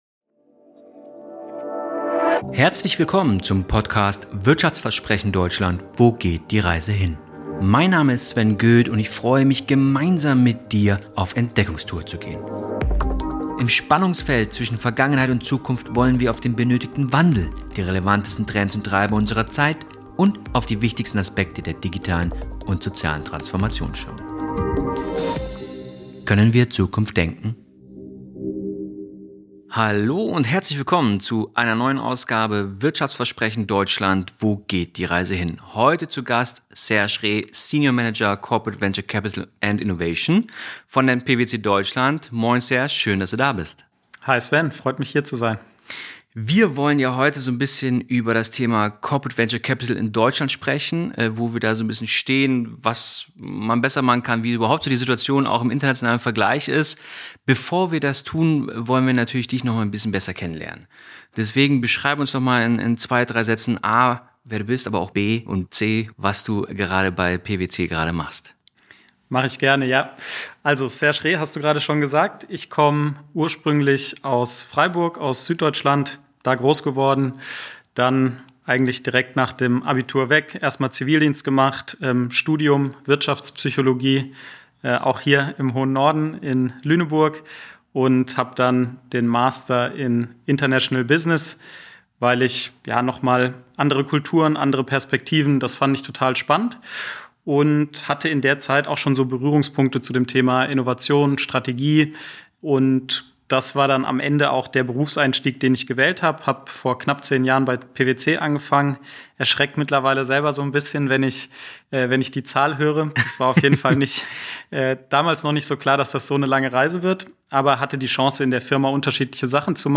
Ein fesselndes Gespräch über den Status Quo, Investitionen und die Zukunft der deutschen Wirtschaft.